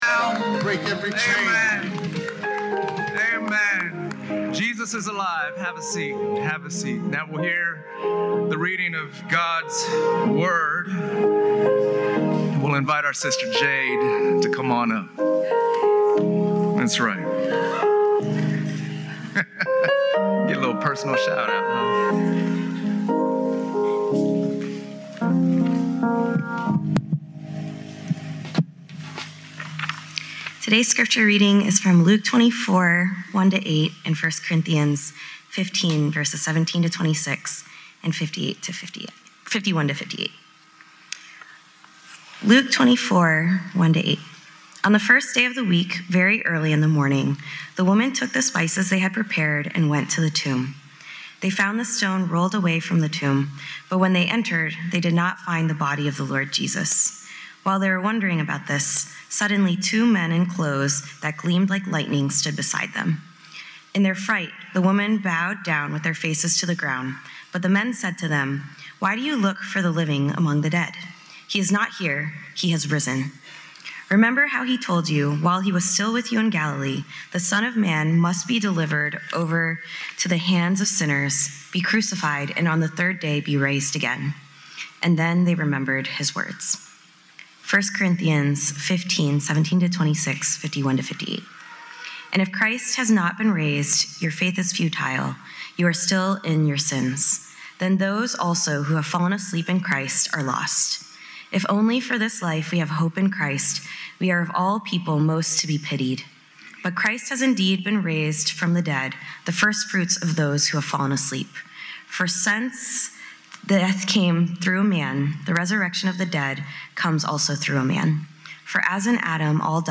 preaches on Easter Sunday from Luke 24:1-8 and 1 Corinthians 15:17-26, 51-58.